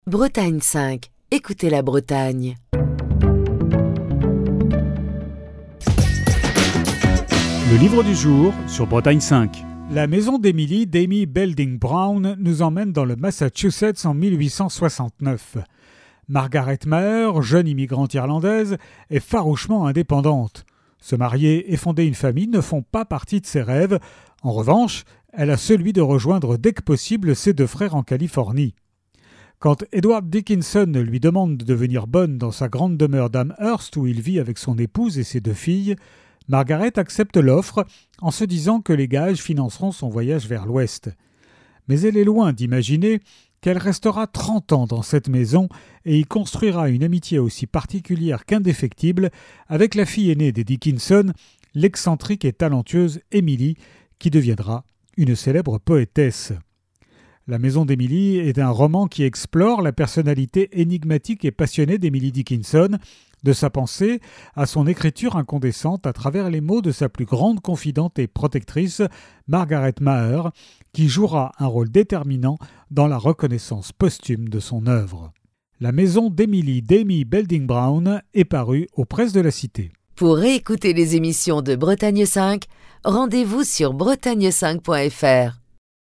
Chronique du 12 juin 2024.